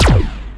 fire_laser1.wav